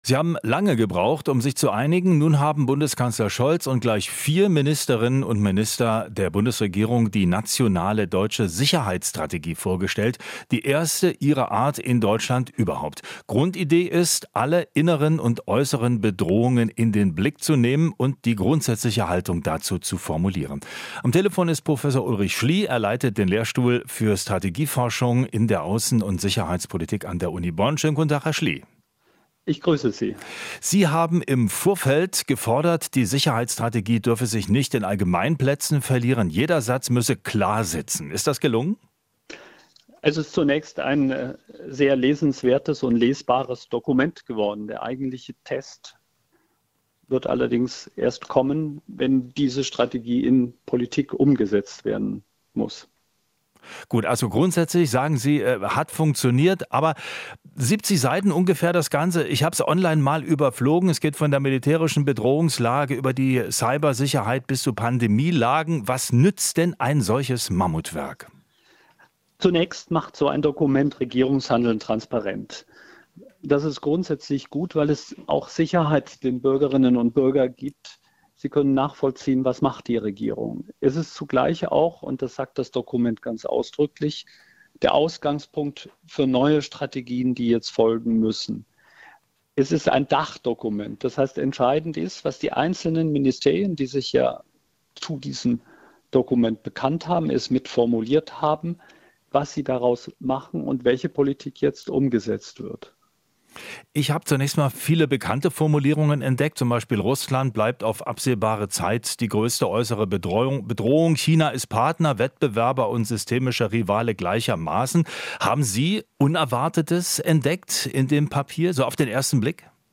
Interview - Sicherheitsexperte: Deutschland braucht einen Nationalen Sicherheitsrat